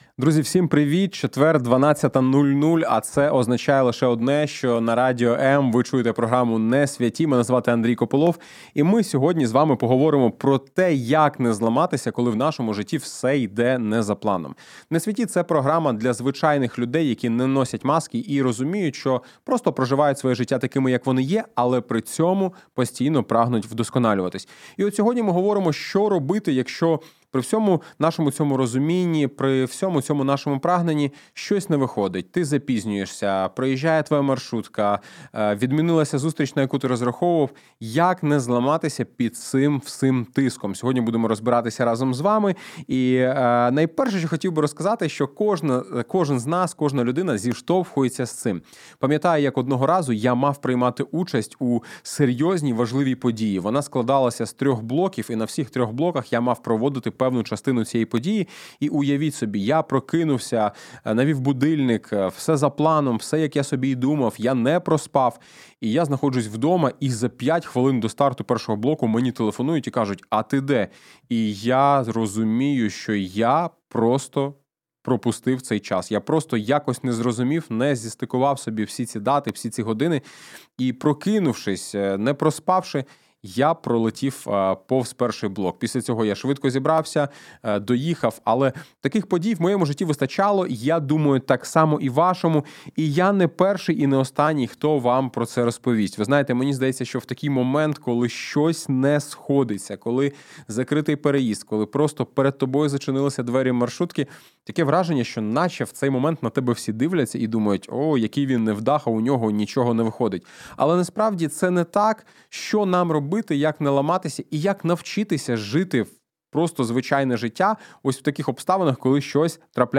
Простий ефір про складне — без пафосу, з іронією, надією і пошуком Бога посеред хаосу.